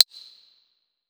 Closed Hats
pcp_click.wav